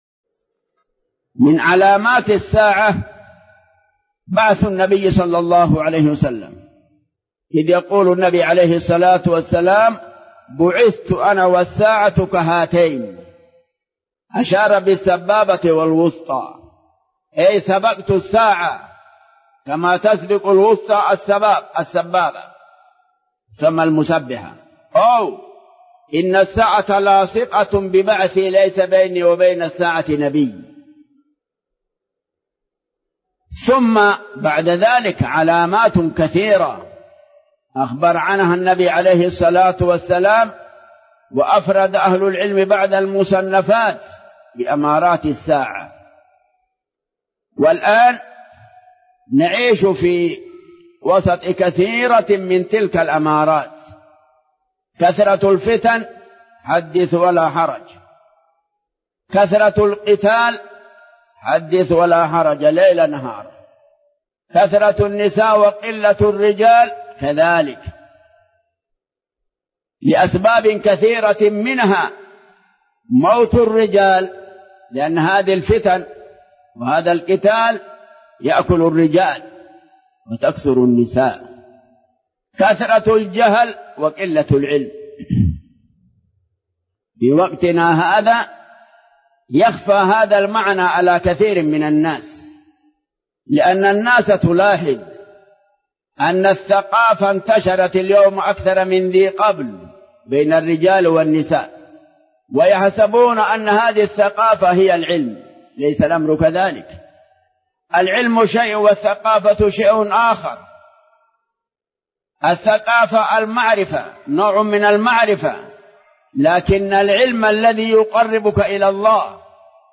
Format: MP3 Mono 22kHz 32Kbps (VBR)